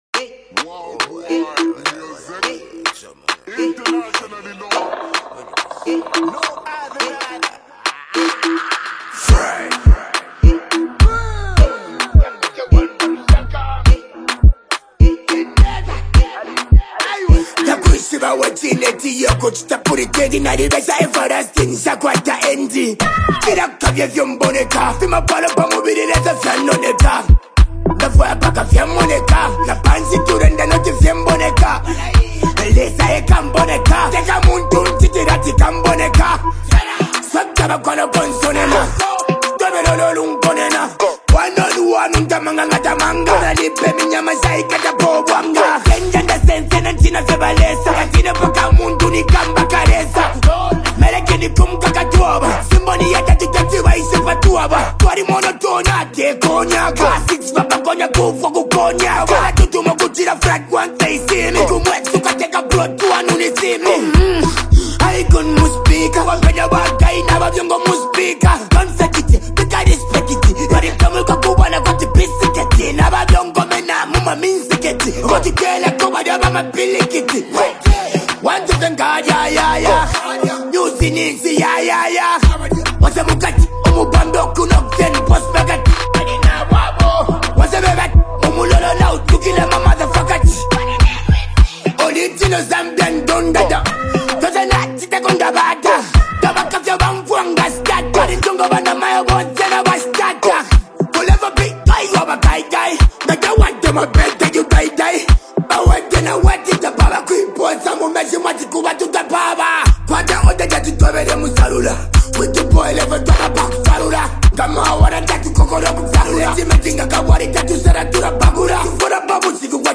Zambian rapper